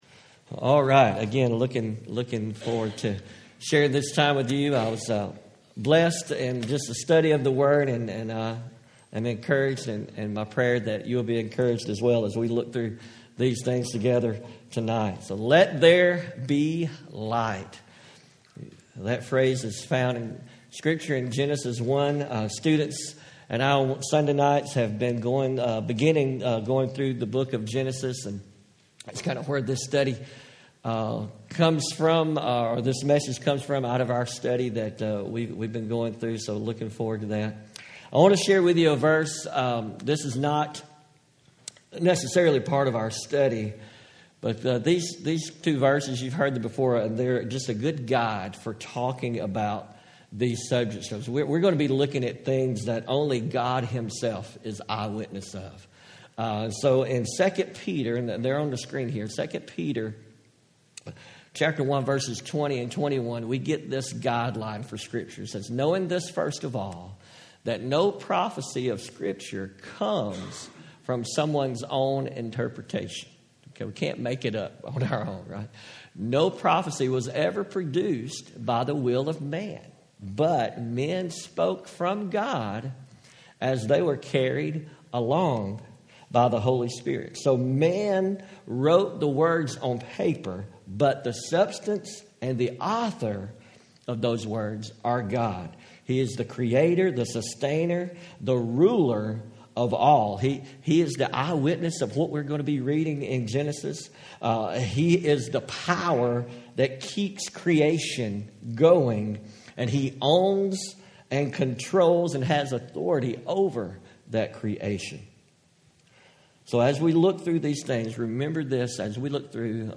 Home › Sermons › Let There Be Light